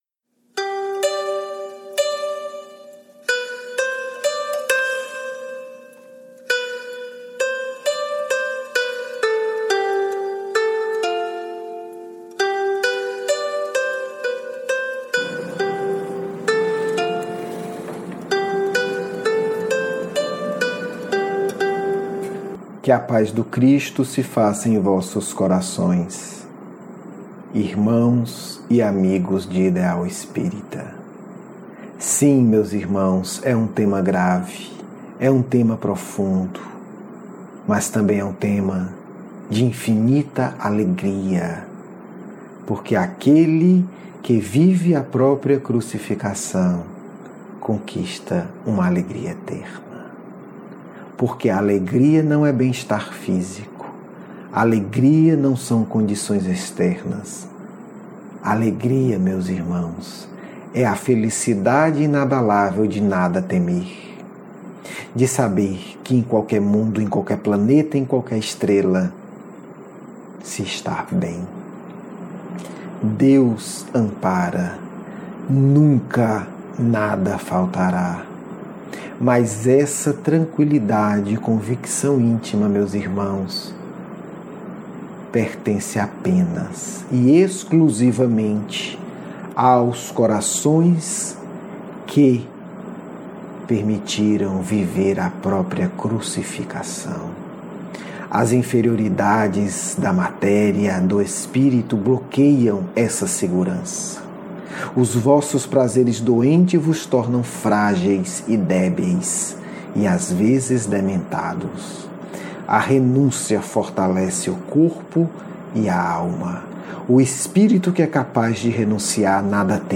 Diálogo mediúnico